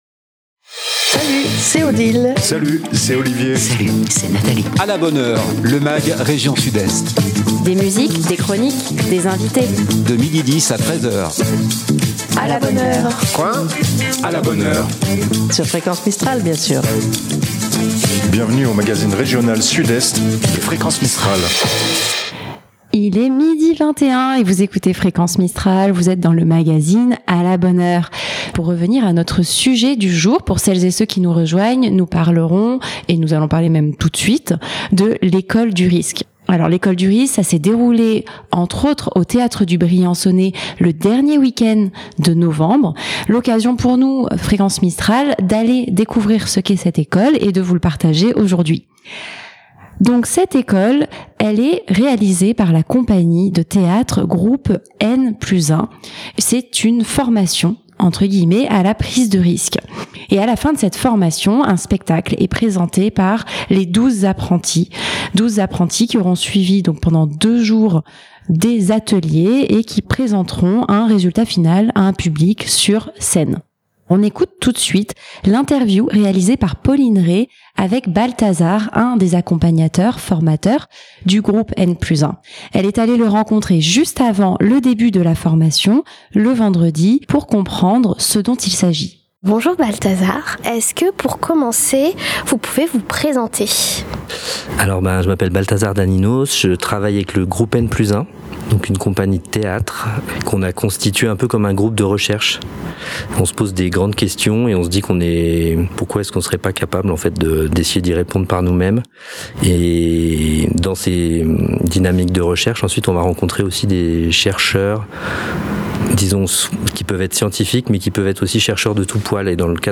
Un format un peu spécial pour ce magazine, sans invité en direct. Aujourd'hui, un focus sur un événement qui s'est déroulé à Briançon. Le week end du 30 novembre 2024, le Théâtre du Briançonnais proposait à 12 volontaires de venir partciper à un stage sur 2 jours, l'école du risque .